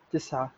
spoken-arabic-digits